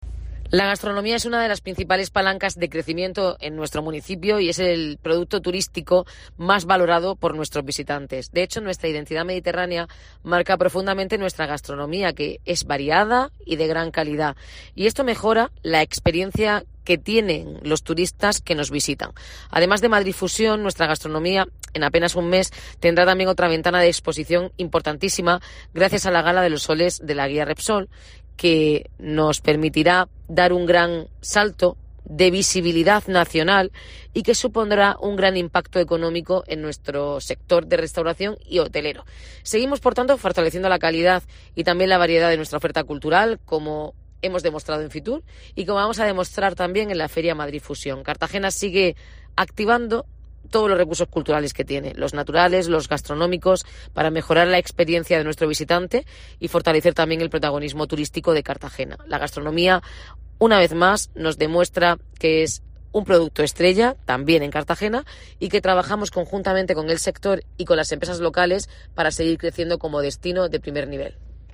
Noelia Arroyo, alcaldesa de Cartagena